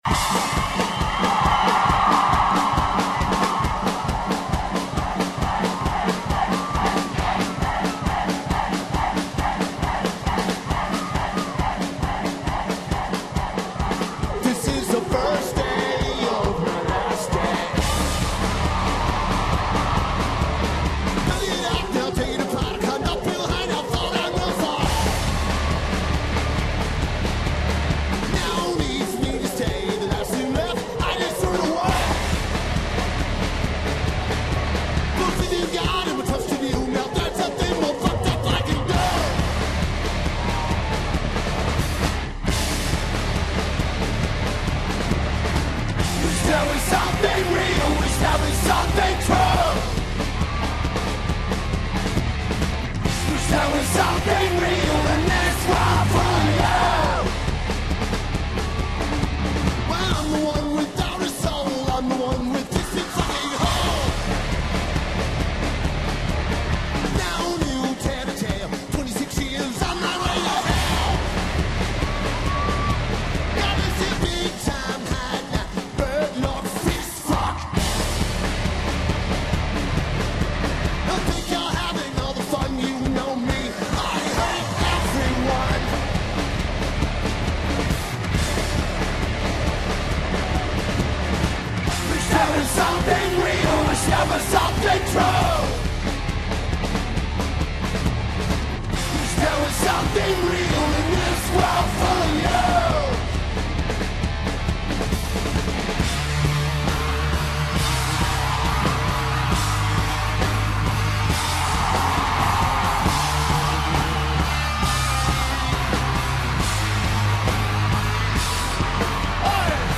Taper: Soundboard
Lineage: Audio - SBD (Lolla Argentina Stream)